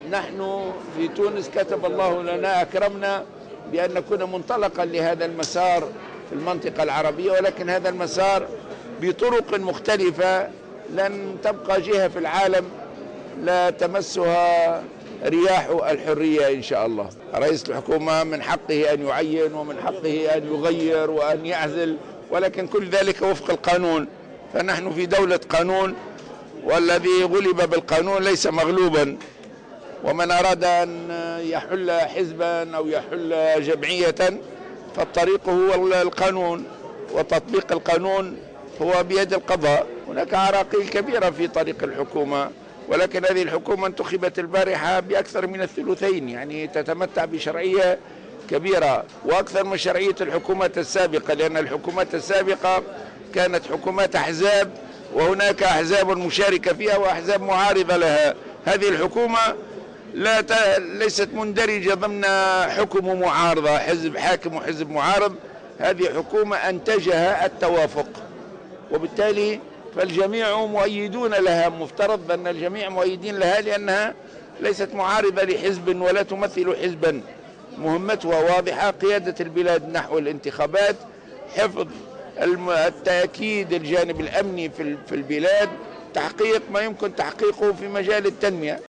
قال رئيس حركة النهضة راشد الغنوشي في تصريحات للإعلاميين عقب تسلّم الحكومة الجديدة مهامّها, إنّ حكومة مهدي جمعة التي حظيت بثقة ثلثي أعضاء المجلس التأسيسي تتمتّع بشرعيّة أكبر من الحكومات السابقة كونها غير متحزّبة.